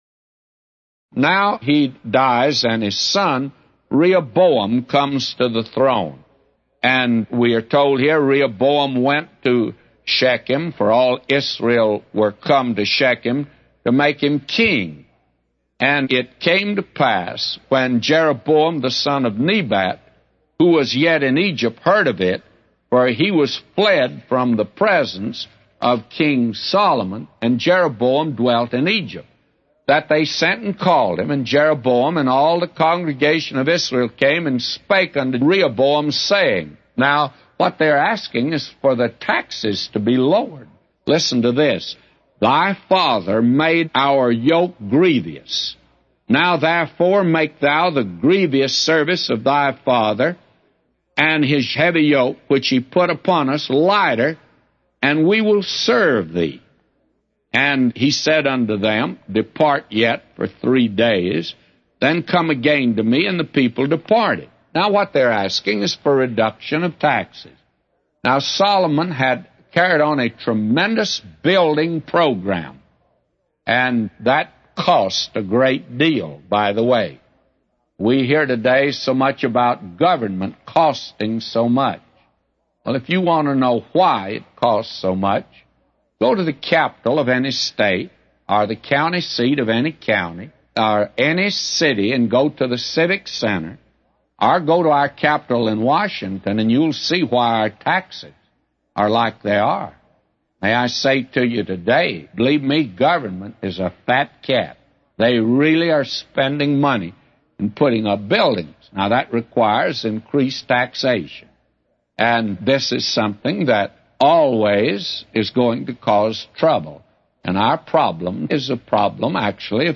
A Commentary By J Vernon MCgee For 1 Kings 12:1-999